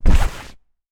Tackle Intense.wav